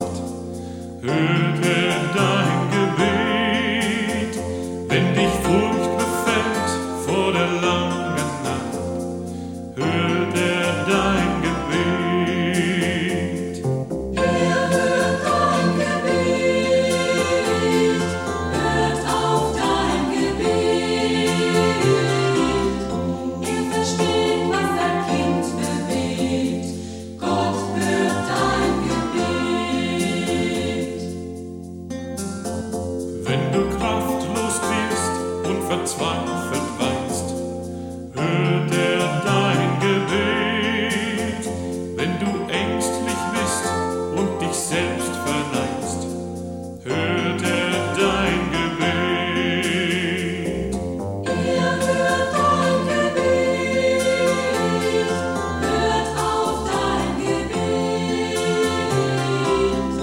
Neuere Gemeindelieder